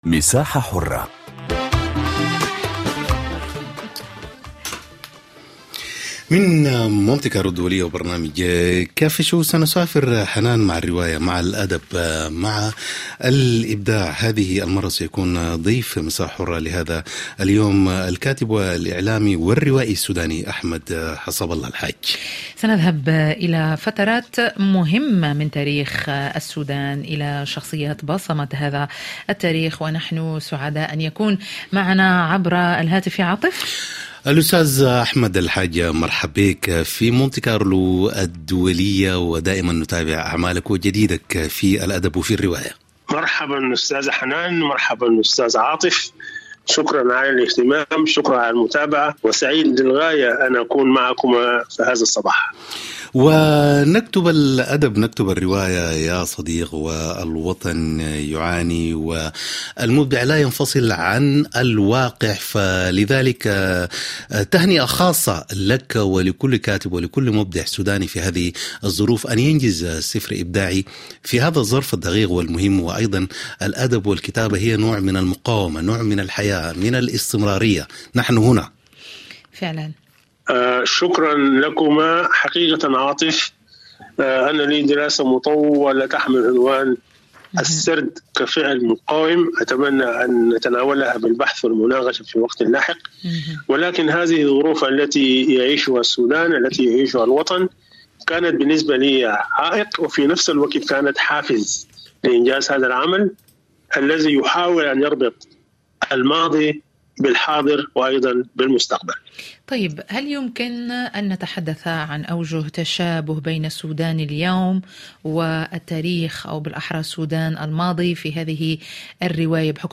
برامج مونت كارلو الدولية من تقديم صحفيين ومذيعين متخصصين وتعتمد في أغلبها على التواصل اليومي مع المستمع من خلال ملفات صحية واجتماعية ذات صلة بالحياة اليومية تهم المرأة والشباب والعائلة، كما أنها تشكل نقطة التقاء الشرق بالغرب والعالم العربي بفرنسا بفضل برامج ثقافية وموسيقية غنية.